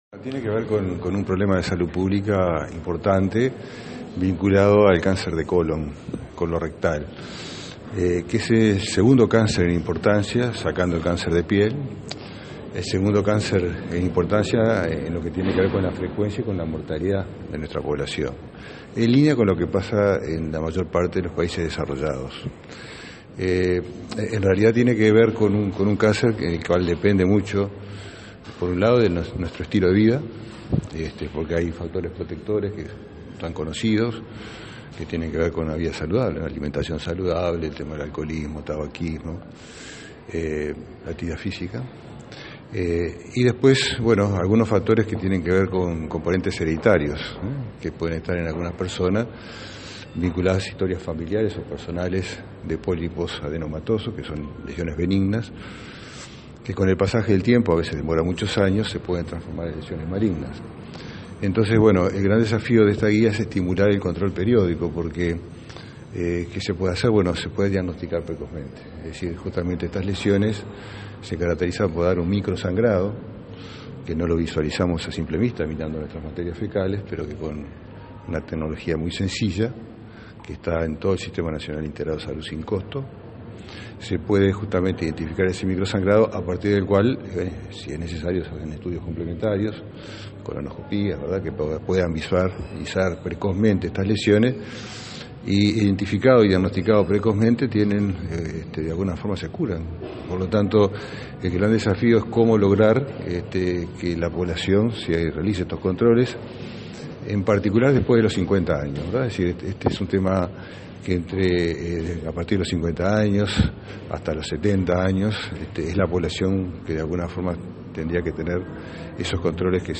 El ministro de Salud Pública, Jorge Basso, sostuvo que la incidencia de cáncer colo-rectal en Uruguay tiene que ver con el estilo de vida y componentes hereditarios, y se ve mayoritariamente en los países desarrollados. Entre los 50 y los 70 años se recomiendan los controles cada dos años.